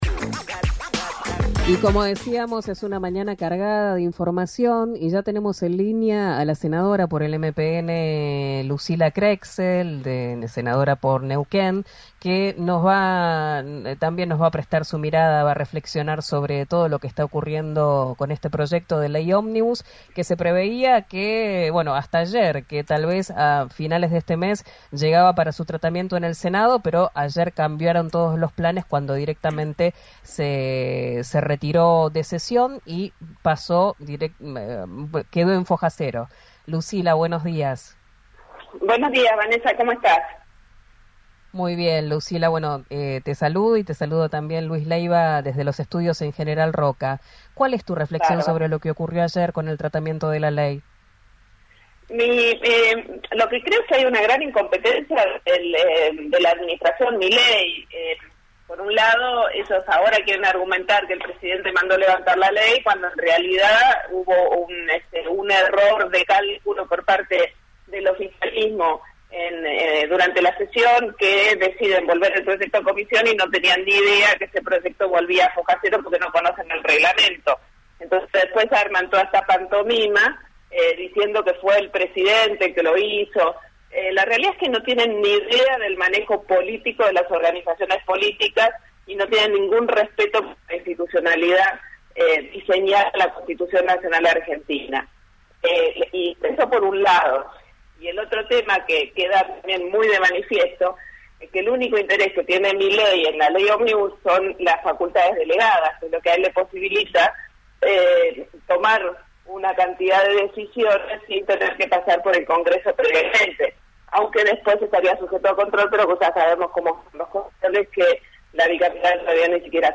Escuchá a la senadora, Lucila Crexell, en RÍO NEGRO RADIO: